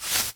Scythe.wav